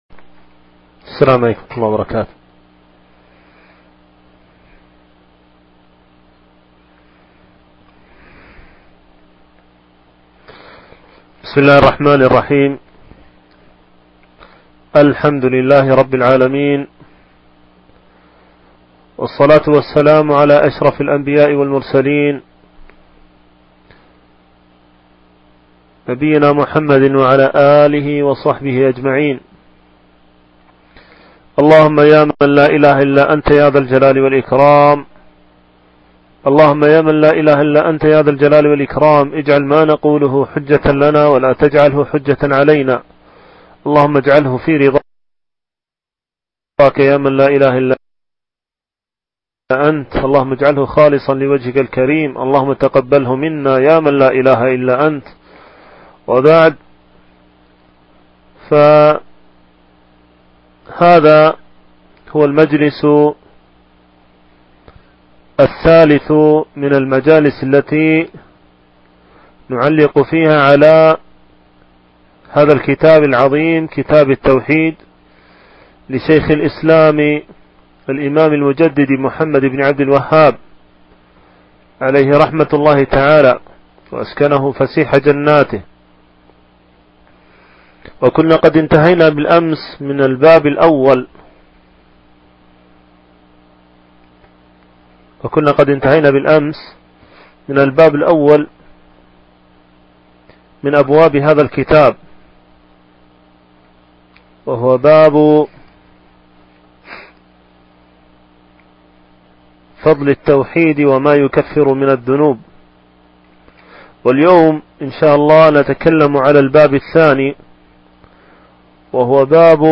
شرح كتاب التوحيد - الدرس الرابع